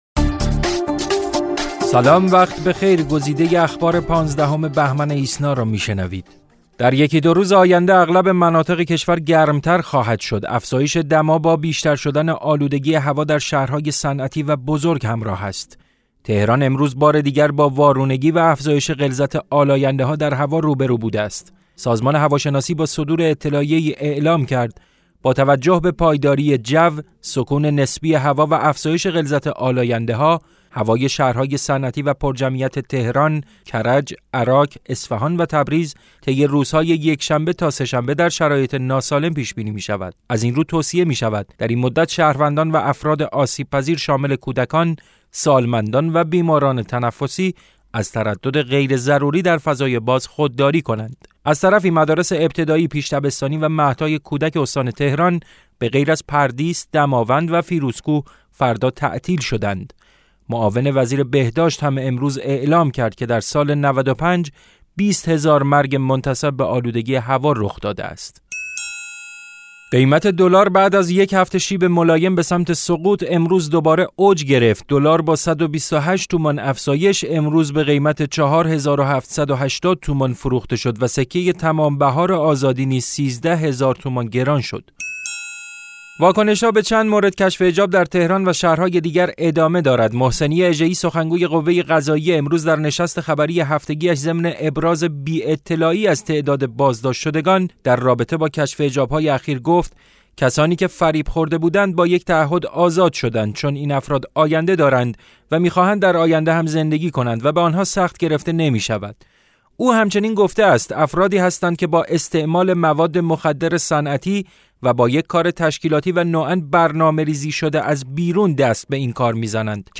صوت / بسته خبری ۱۵ بهمن ۹۶